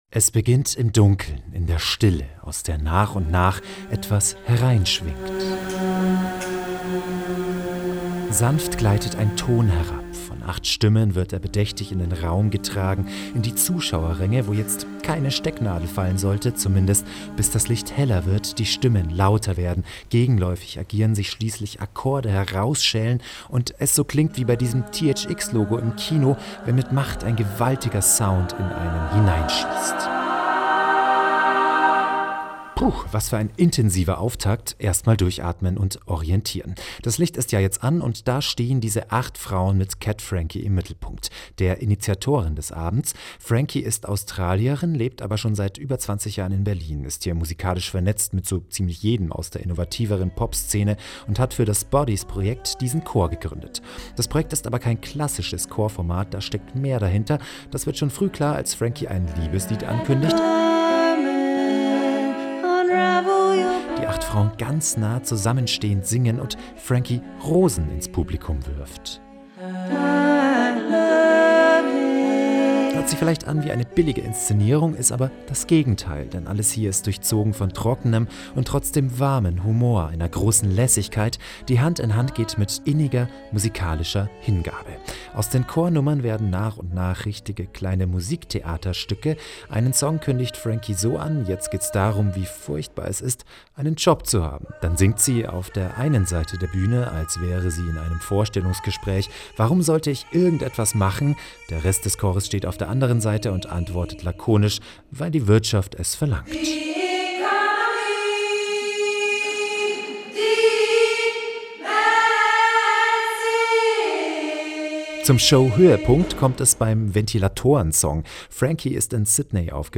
Acht Frauen, acht Stimmen, acht Körper, die Klang erzeugen.